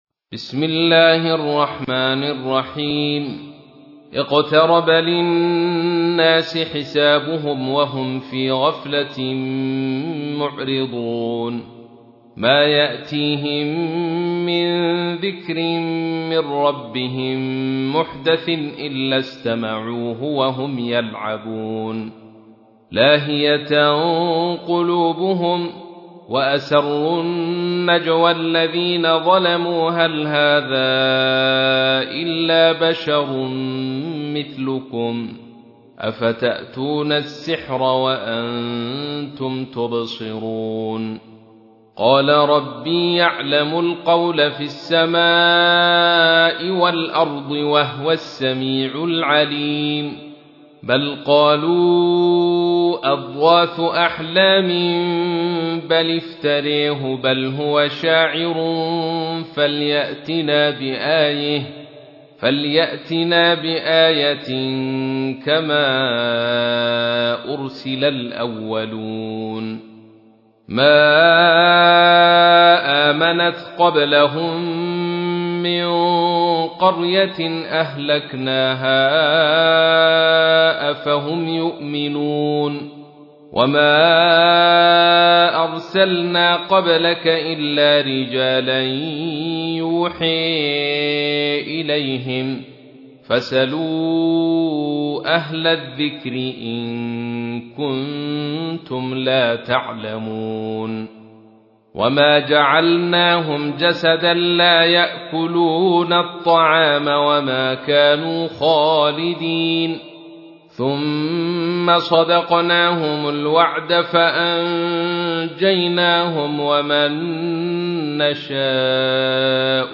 تحميل : 21. سورة الأنبياء / القارئ عبد الرشيد صوفي / القرآن الكريم / موقع يا حسين